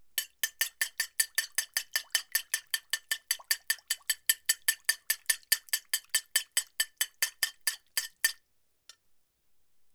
Batiendo huevos
batir
revolver
Cocina - Zona de preelaboración
Sonidos: Acciones humanas